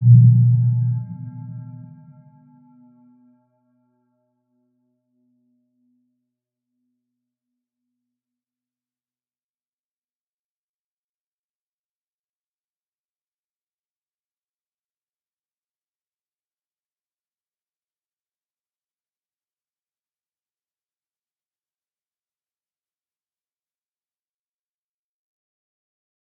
Round-Bell-B2-p.wav